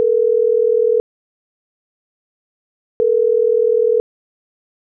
klingelton.wav